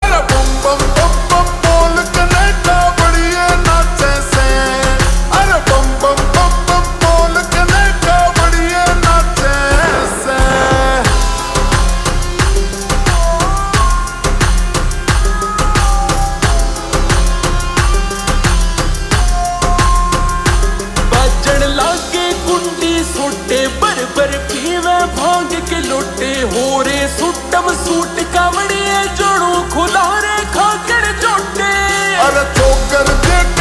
Haryanvi Songs
Slowed + Reverb